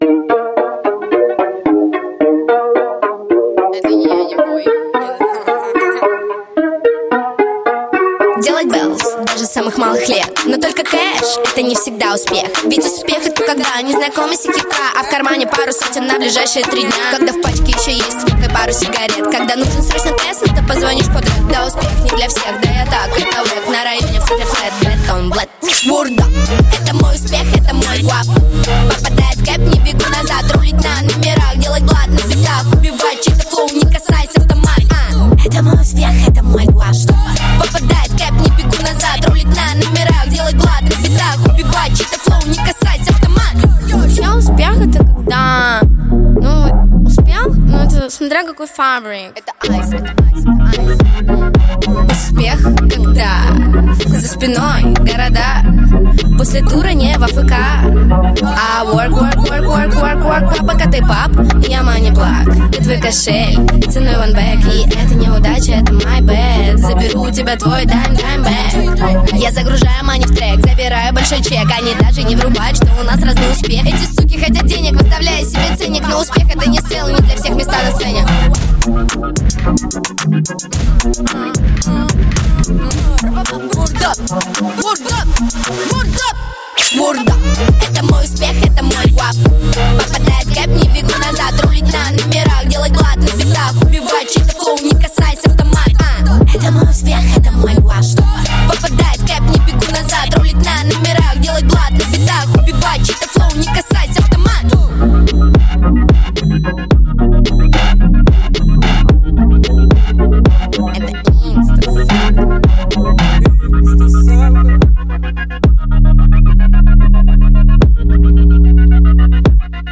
Жанр: Жанры / Хип-хоп